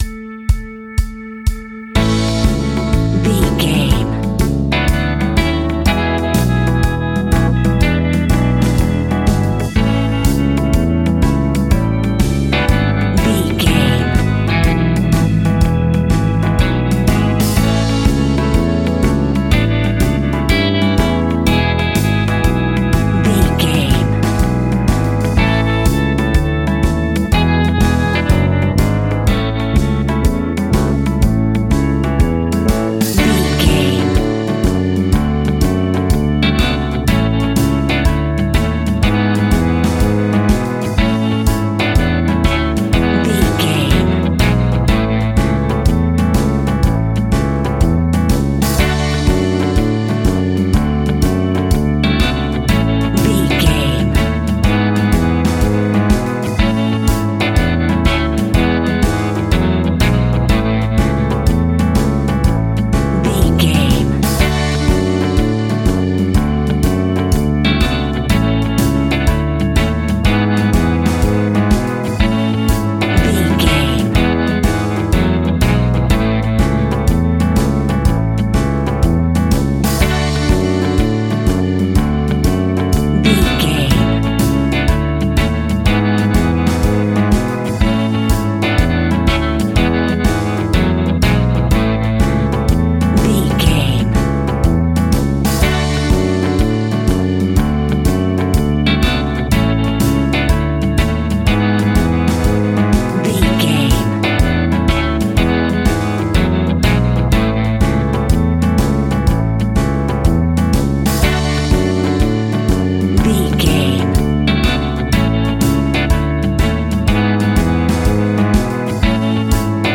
Bouncy Happy Sixties Pop.
Ionian/Major
pop rock
Sunshine pop
peppy
upbeat
bright
drums
bass guitar
electric guitar
keyboards
hammond organ
acoustic guitar
percussion